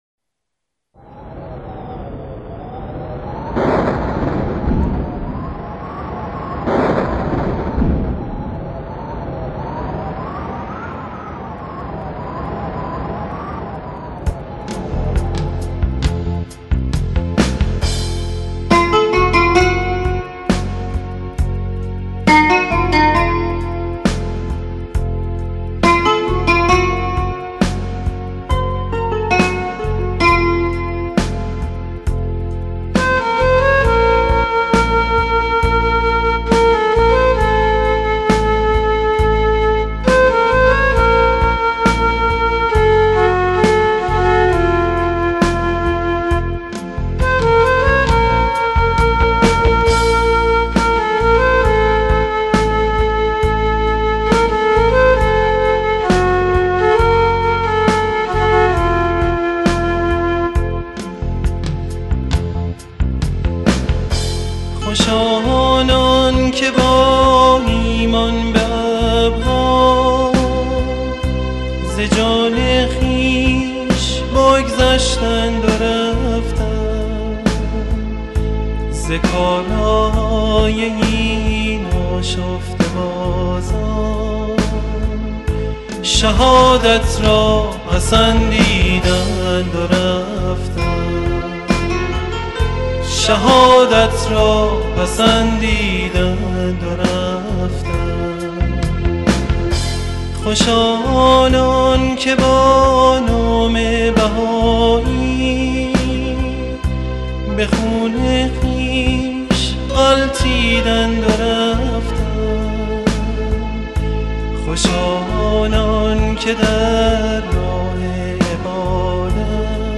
سرود - شماره 7 | تعالیم و عقاید آئین بهائی